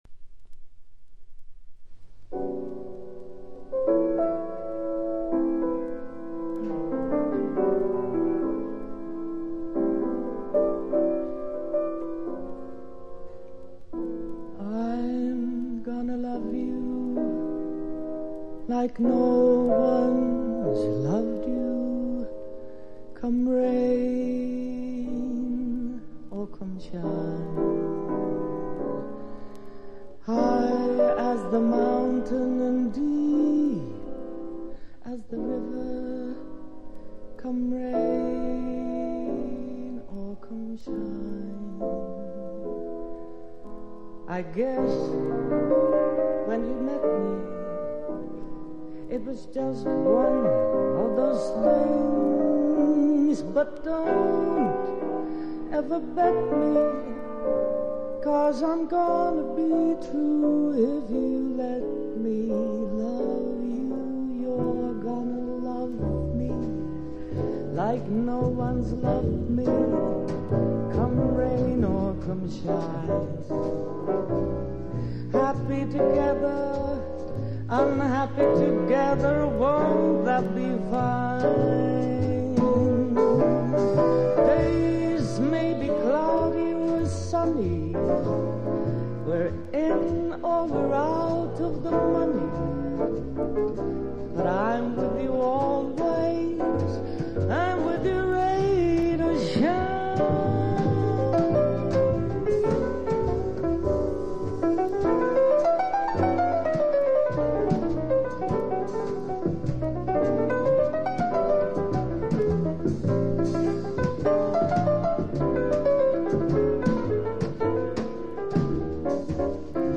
4曲トリオ、4曲ボーカル入りの全8曲。
Genre JAZZ VOCAL